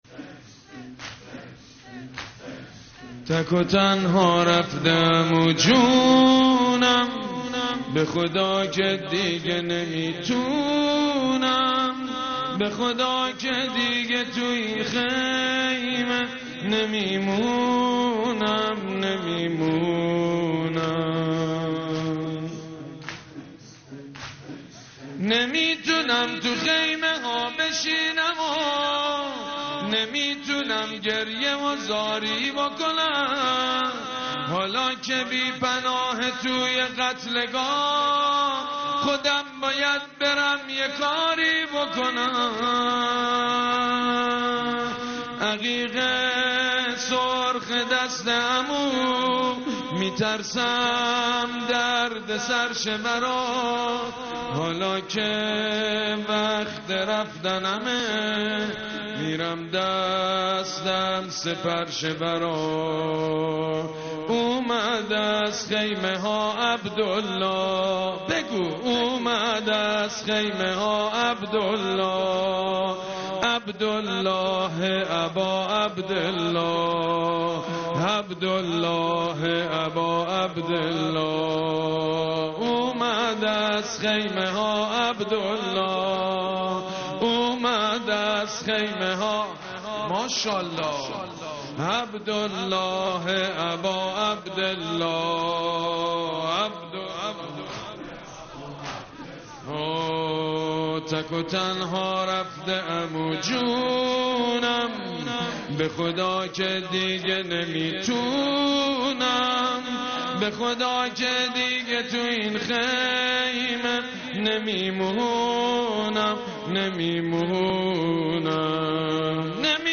صوت | مداحی شب پنجم محرم با نوای سید مجید بنی فاطمه